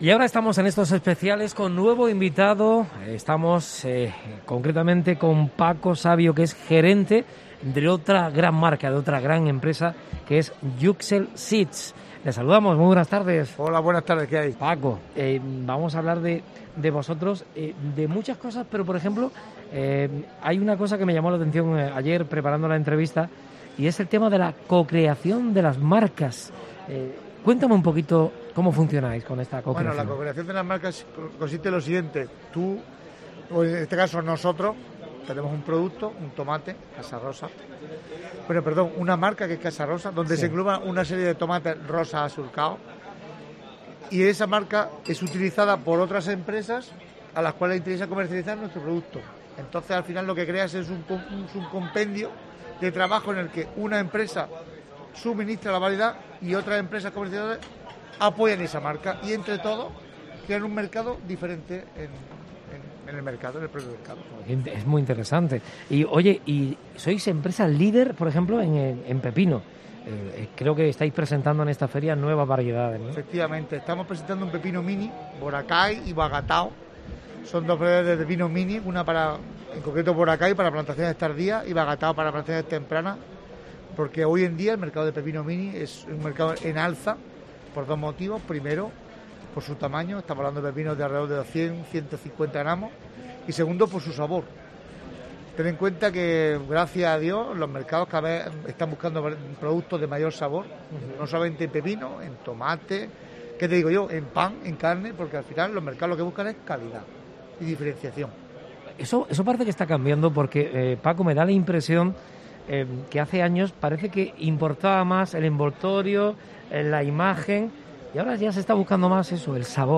AUDIO: Especial Fruit Attraction en COPE Almería. Entrevista